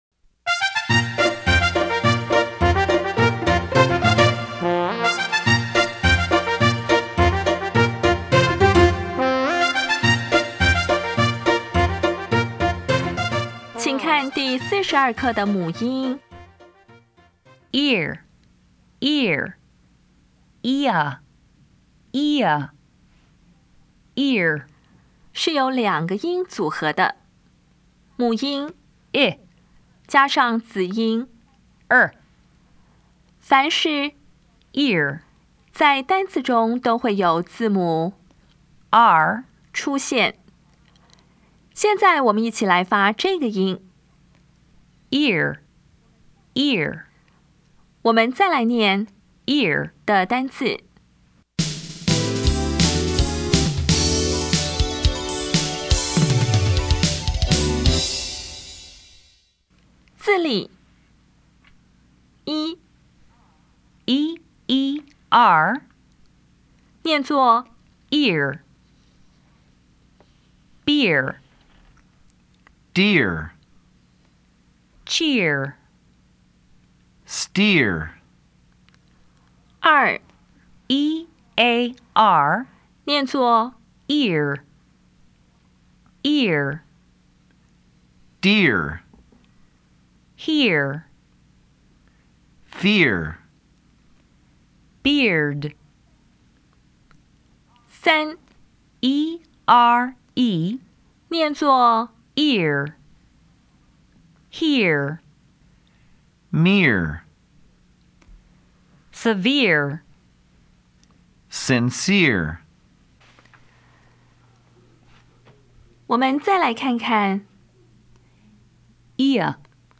当前位置：Home 英语教材 KK 音标发音 母音部分-3: 双母音 [ɪr] [iə]
音标讲解第四十二课
[bɪr]
[ˋriəl]
[aɪˋdiə]